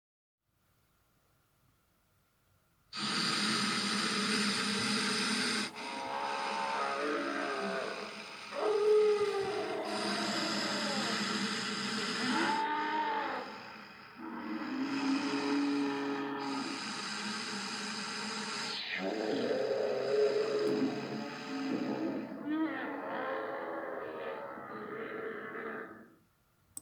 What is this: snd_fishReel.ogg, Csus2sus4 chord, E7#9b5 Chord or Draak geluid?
Draak geluid